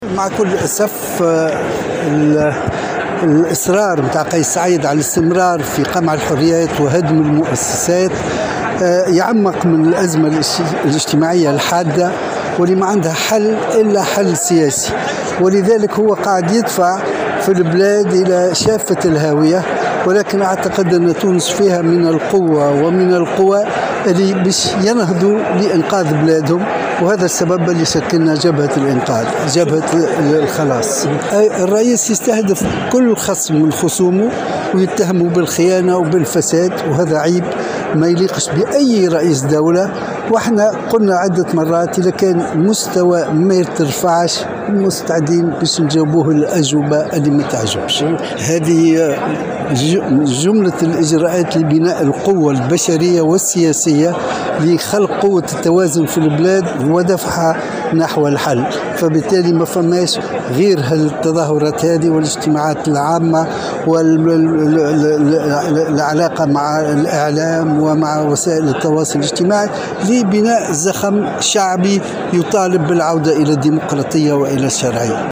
قال مؤسس ما يعرف بـ"جبهة الخلاص"، أحمد نجيب الشابي، في تصريح لمراسل الجوهرة اف أم، اليوم الأحد، إن رئيس الجمهورية قيس سعيد يواصل الدفع بالبلاد نحو حافة الهاوية وتعميق الأزمة، من خلال إصراره على قمع الحريات وهدم المؤسسات.
وانتقد الشابي، لدى مشاركته في وقفة احتجاجية بشارع الحبيب بورقيبة بالعاصمة، دعت إليها حملة "مواطنون ضد الانقلاب"، تفرد سعيد بالحكم، واستهداف كل خصومه وتخوينهم، وهو ما وصفه بأنه "تصرف غير لائق برئيس دولة".